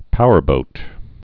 (pouər-bōt)